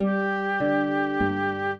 flute-harp